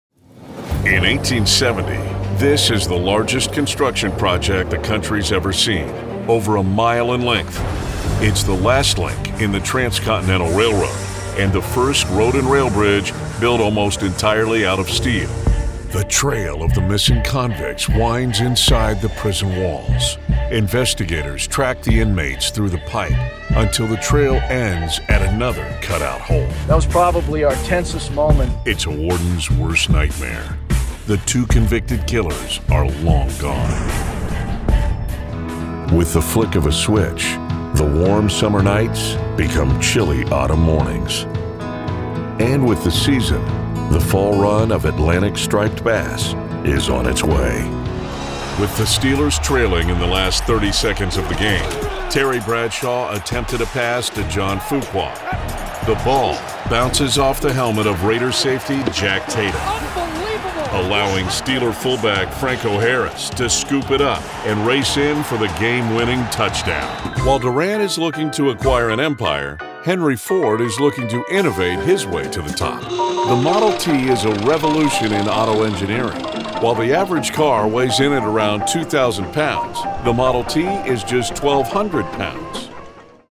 Mature Adult, Adult
Has Own Studio
southern us
standard us
cinema trailer
commercial
documentary